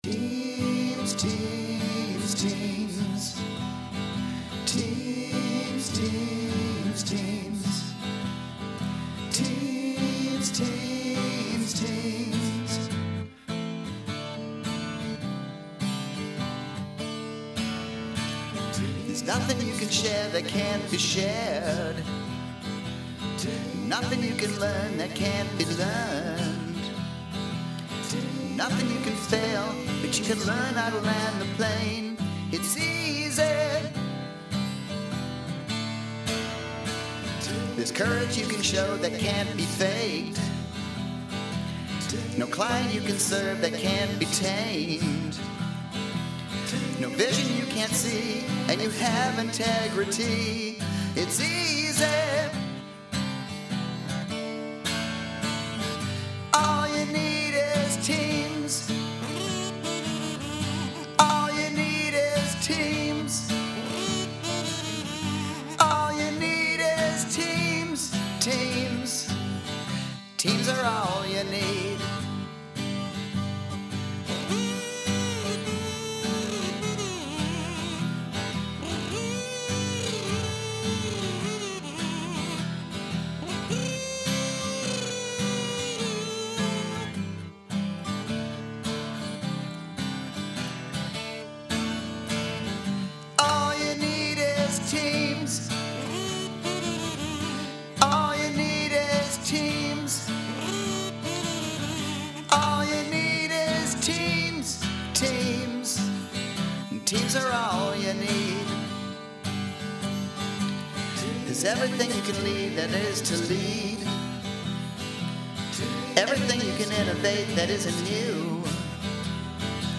Lastly, here’s some old CPI/PW music we had during the party, along with the words to the song we sang.
Chorus